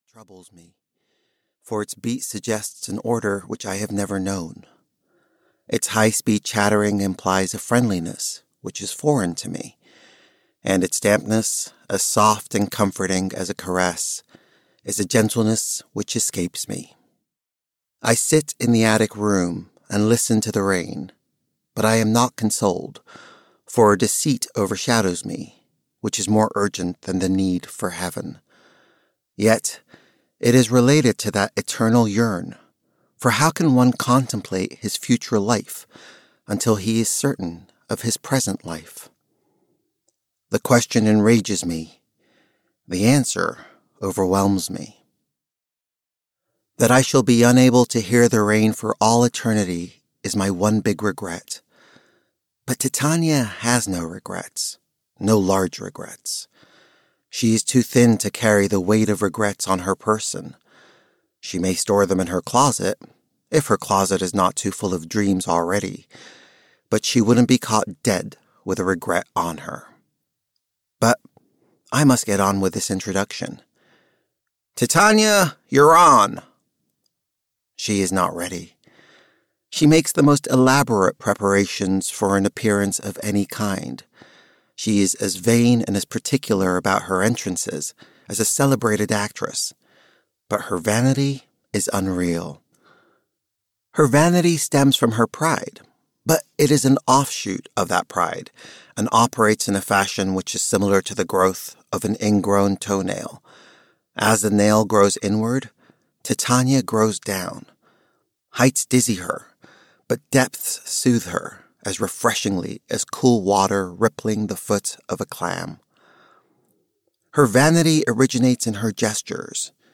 The Gaudy Image (EN) audiokniha
Ukázka z knihy